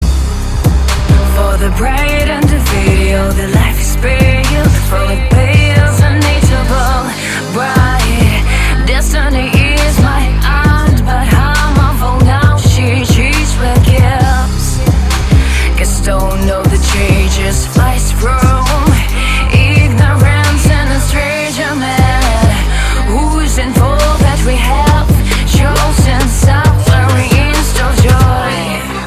• Качество: 266, Stereo
женский вокал
Trap
чувственные
качающие
сексуальный голос